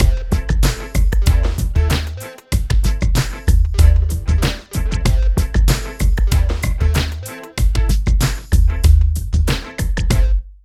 62 LOOP   -R.wav